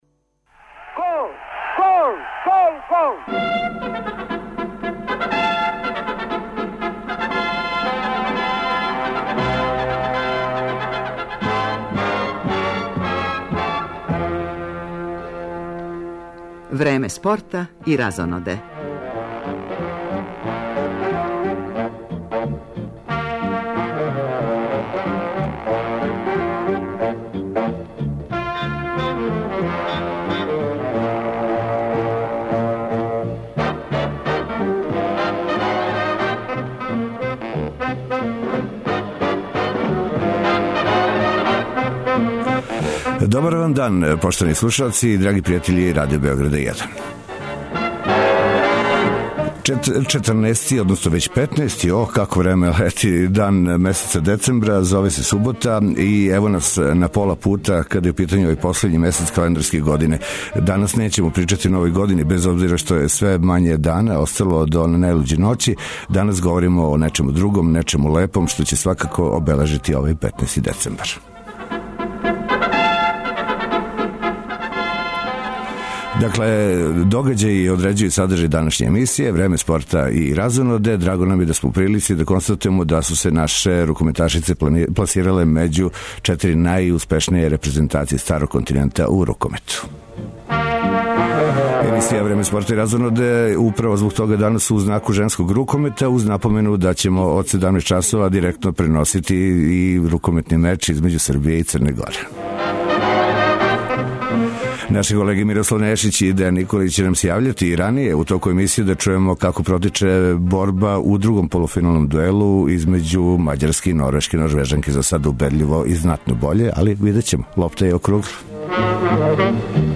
Од 17 часова преносимо полуфинални меч између Србије и Црне Горе, из београдске Комбанк арене.